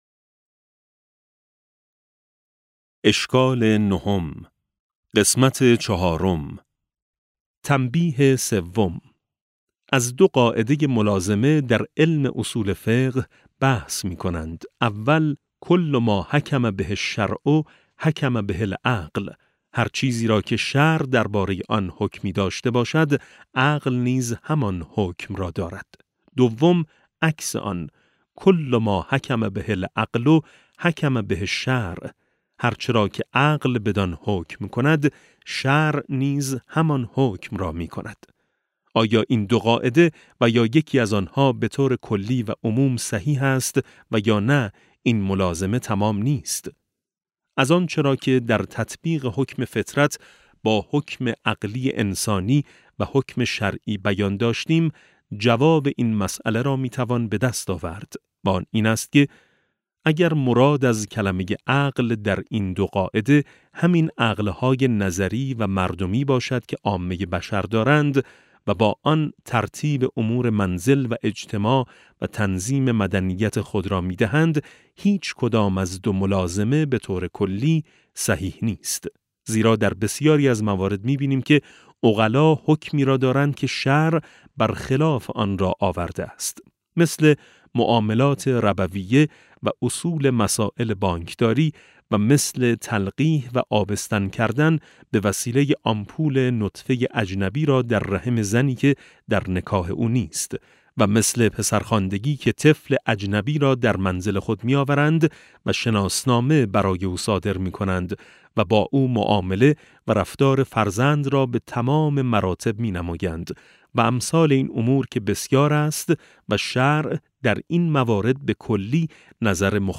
کتاب صوتی نور ملکوت قرآن - ج2 - جلسه27